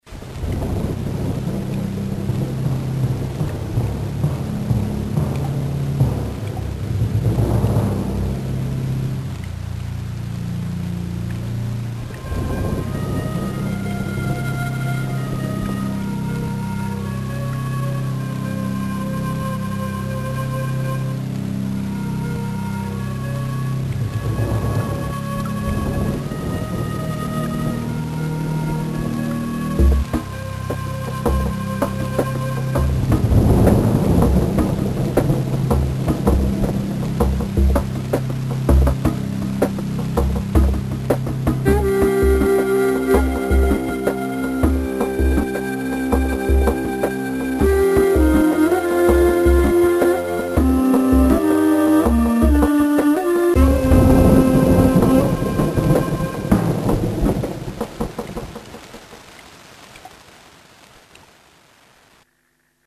frightening, and eerie.
or deep celli and contrabasses.
After that I converted the samples to 8 bit Mono.